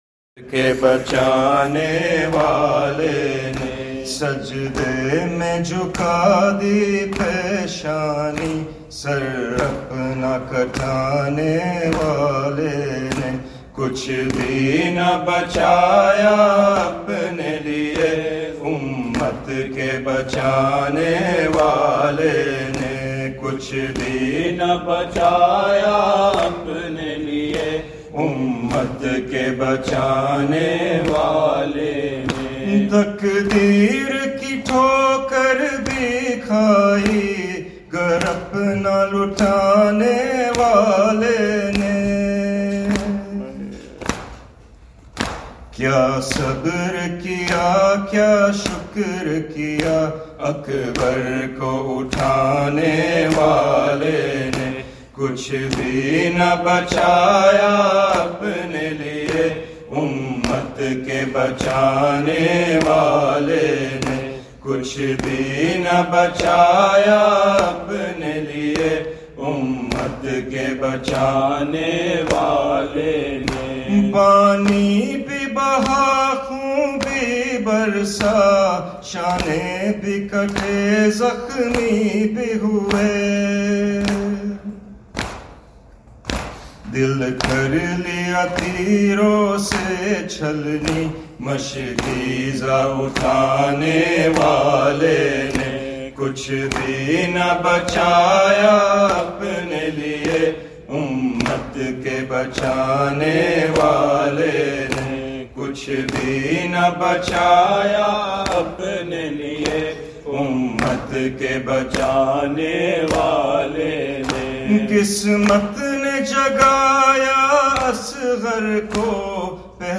Non Saff Calssic Marsia / Nawha